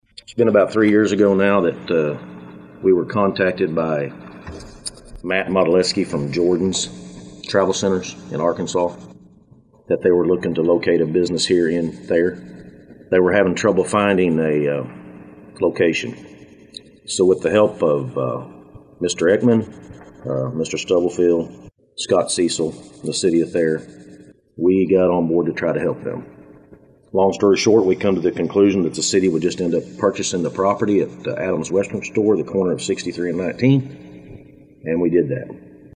One of those projects was $500,000 for the Highway 63/19 project in Thayer for water and sewer infrastructure. Today the City of Thayer held a press conference to talk about their plans for the project. Mayor Ken Cotham talked about how it all started